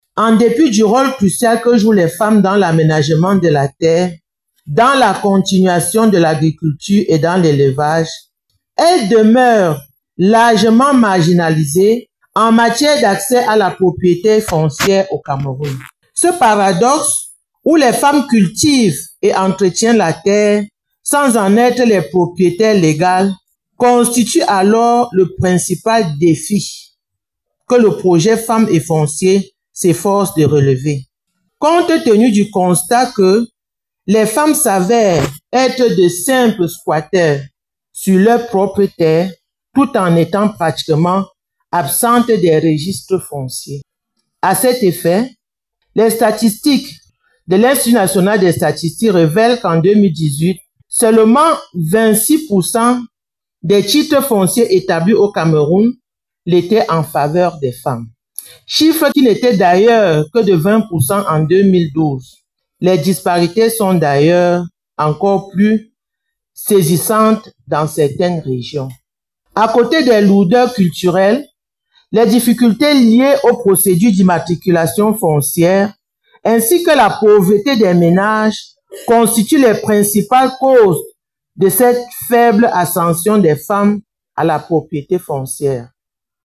Le Gouverneur de la région du Centre s’est exprimé au cours du forum national sur les femmes et le foncier qui s’est tenu le jeudi 07 novembre 2024 à Yaoundé, dans la salle des réunions du conseil régional pour le Centre.
Extrait du discours